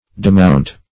Demount \De*mount"\